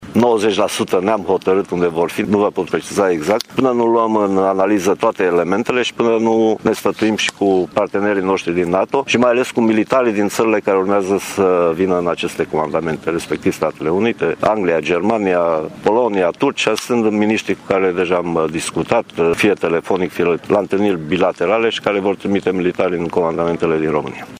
Declarația îi aparține ministrului Apărării Naționale, Mircea Dușa, care s-a aflat astăzi la Tîrgu-Mureș pentru o ceremonie militară.
Ministrul Mircea Dușa: